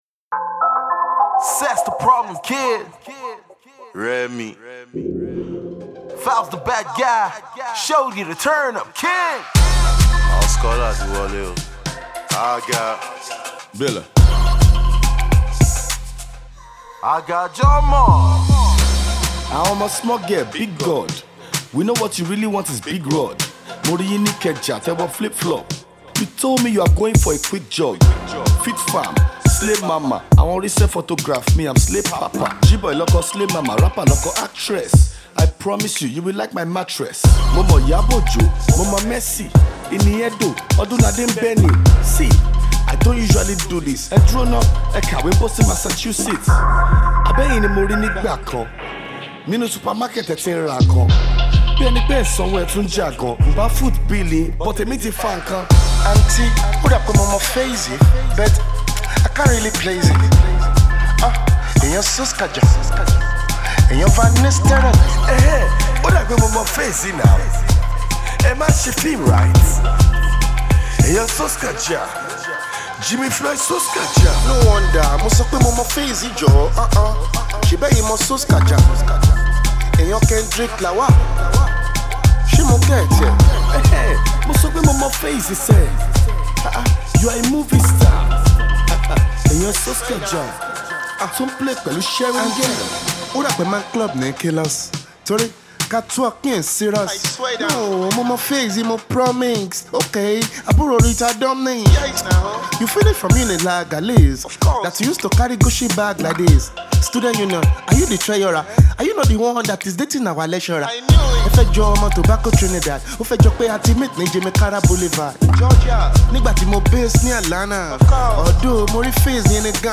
comical hip-hop masterpiece